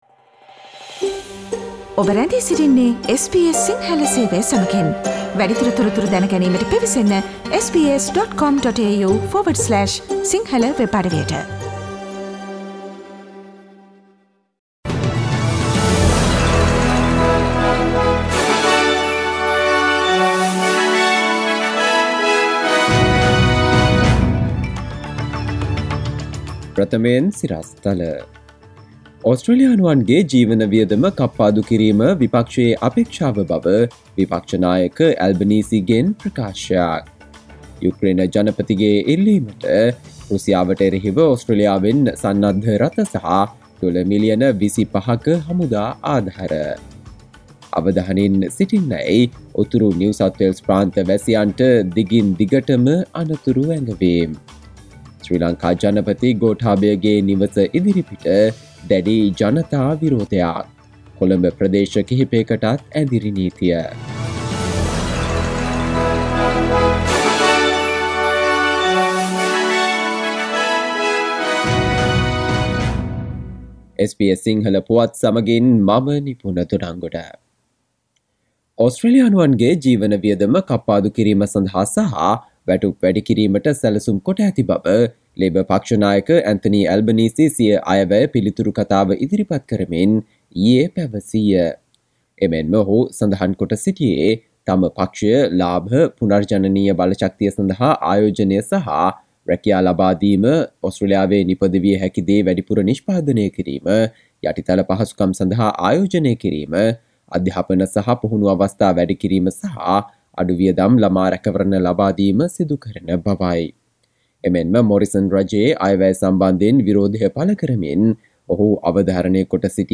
Listen to the latest news from Australia, Sri Lanka, on SBS Sinhala radio news bulletin – Friday 01 April 2022